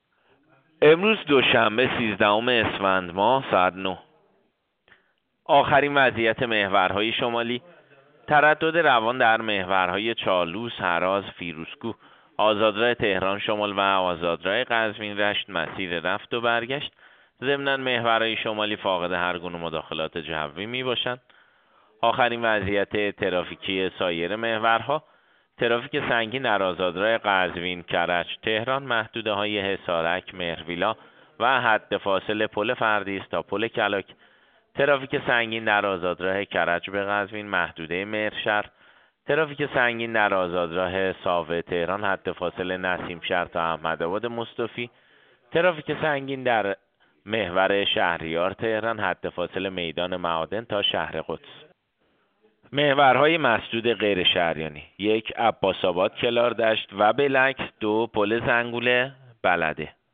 گزارش رادیو اینترنتی از آخرین وضعیت ترافیکی جاده‌ها ساعت ۹ سیزدهم اسفند؛